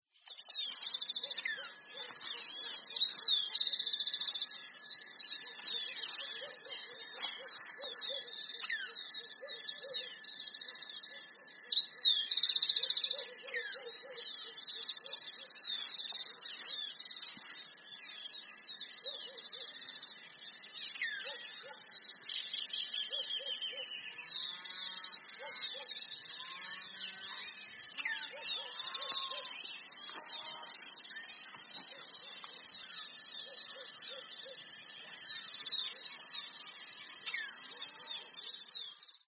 Fiofío Grande (Elaenia spectabilis)
Provincia / Departamento: Entre Ríos
Localidad o área protegida: La Argentina
Condición: Silvestre
Certeza: Vocalización Grabada